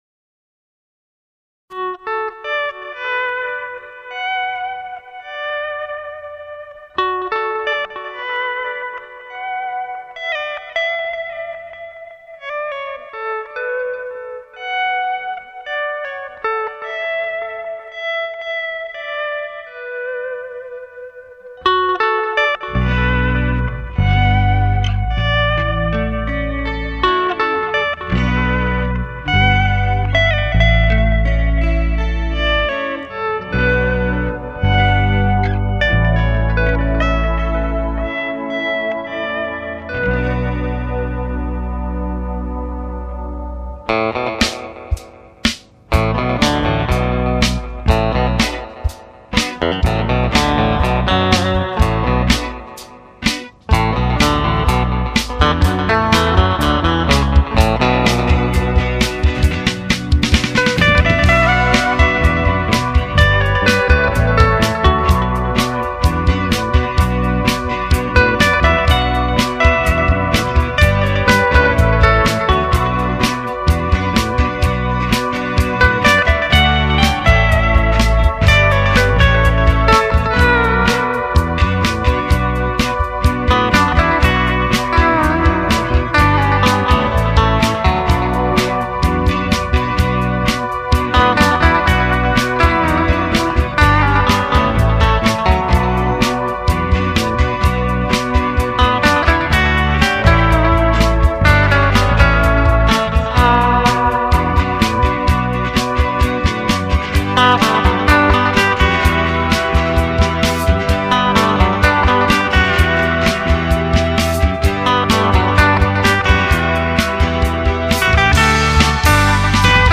Lead guitar
Bass guitar
Rhythm guitar
Keyboards
Drums